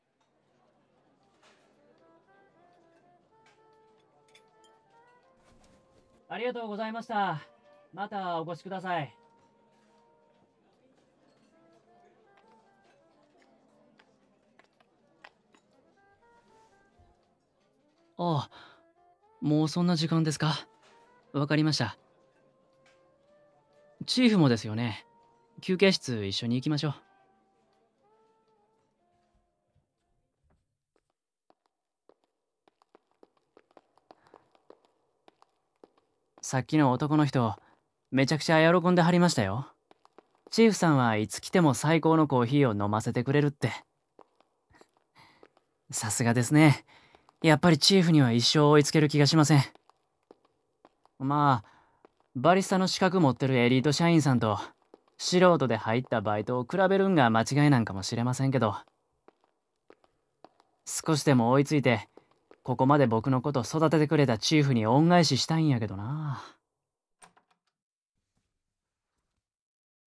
関西弁彼氏と育む、ほのぼのご近所ラブストーリー！
【本編サンプルボイス】